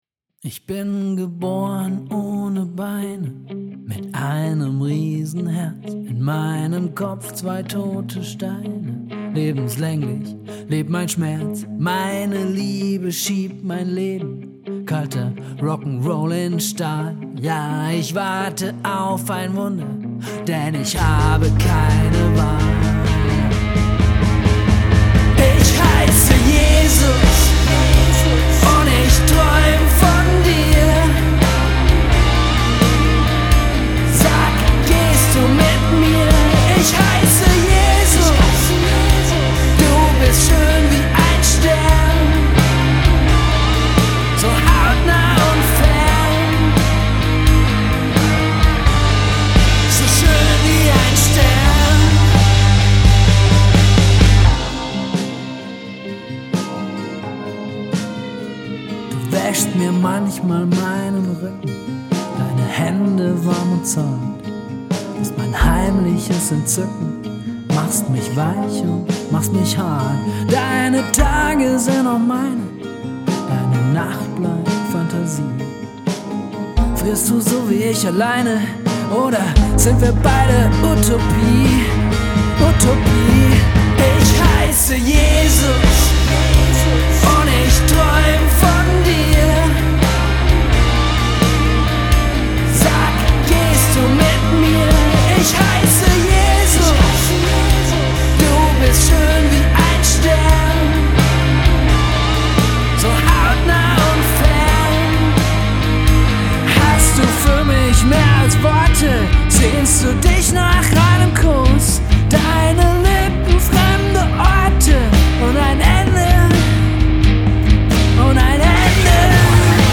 Dramatischer Deutschrock